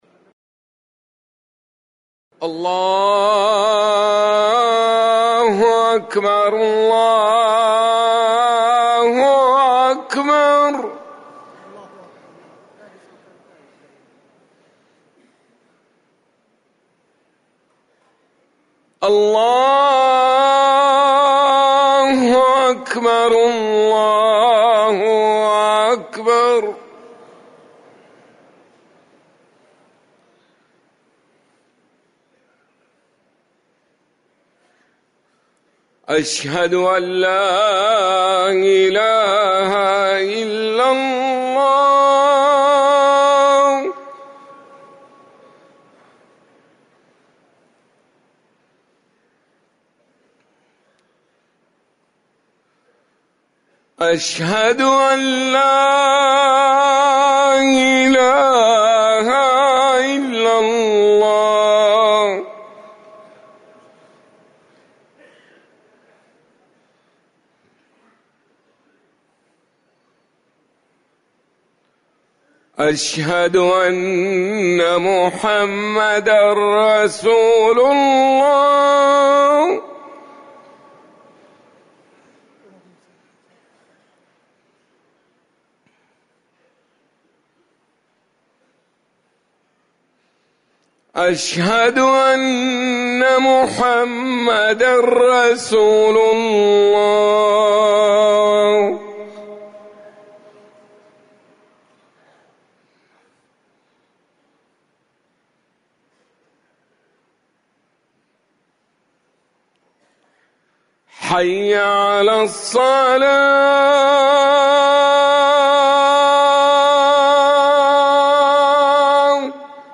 أذان العشاء - الموقع الرسمي لرئاسة الشؤون الدينية بالمسجد النبوي والمسجد الحرام
تاريخ النشر ٨ رجب ١٤٤١ هـ المكان: المسجد النبوي الشيخ